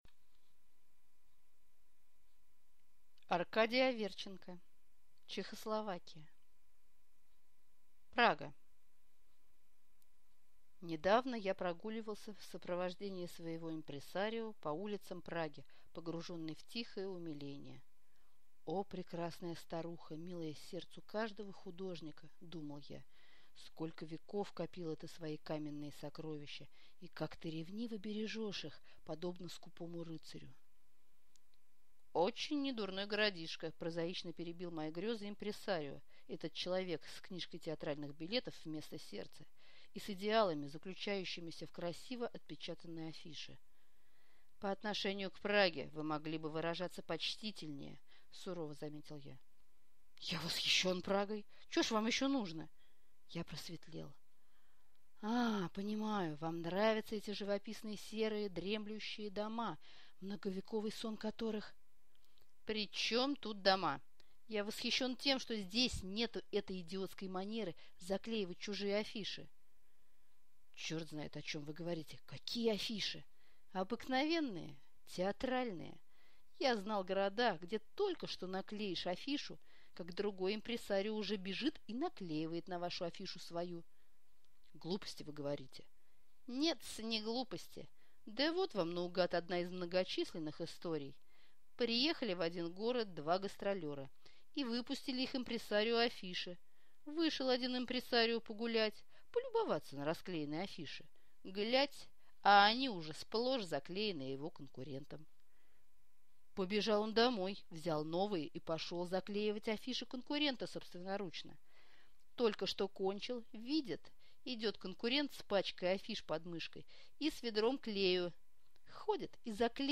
Аудиокнига Чехо-Словакия | Библиотека аудиокниг